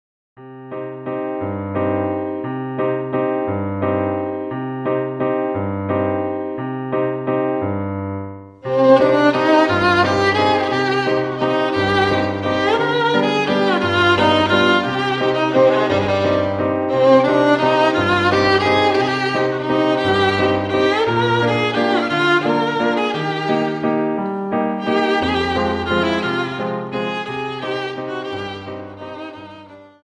Viennese Waltz 58 Song